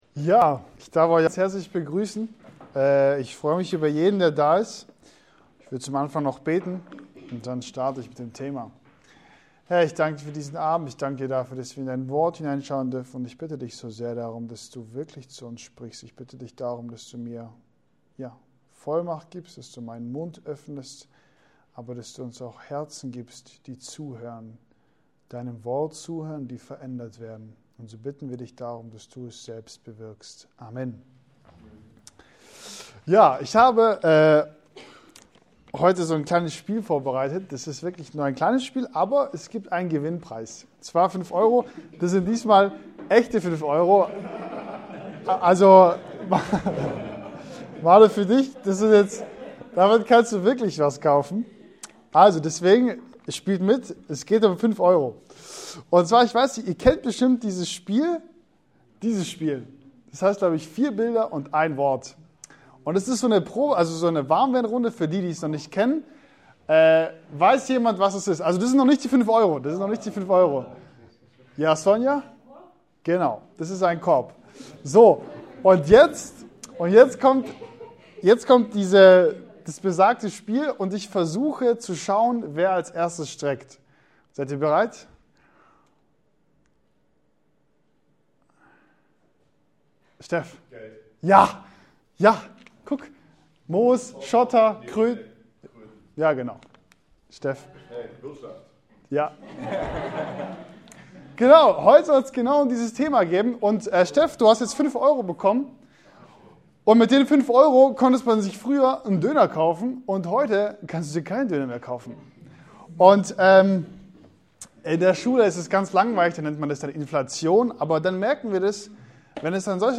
Jakobus Passage: Jakobus 5,1-6 Dienstart: Jugendstunden « Leben in Fülle